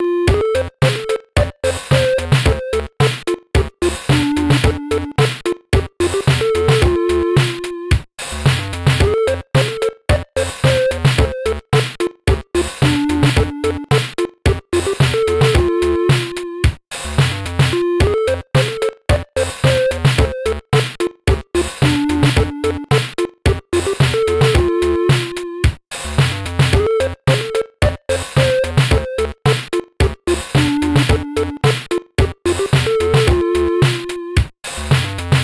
though i could remake it as an .it (this was made in the qsound chip)